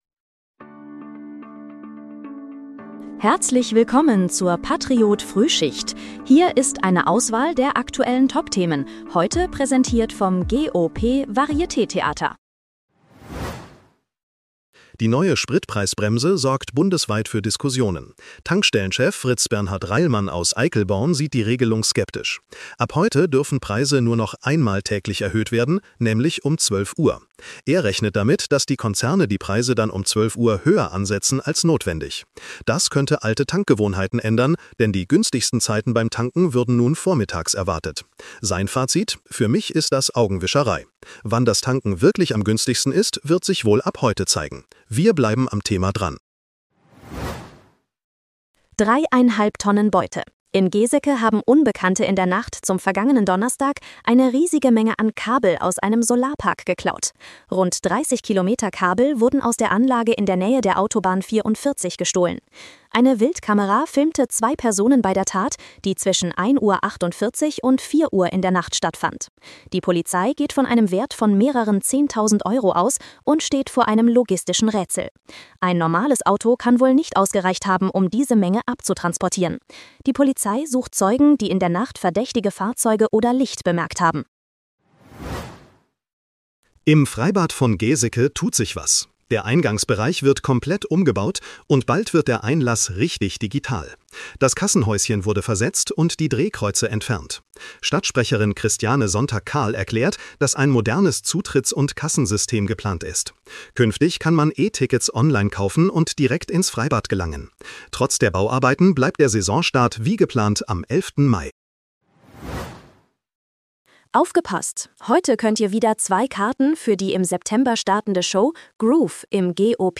Dein morgendliches News-Update
mit Hilfe von Künstlicher Intelligenz.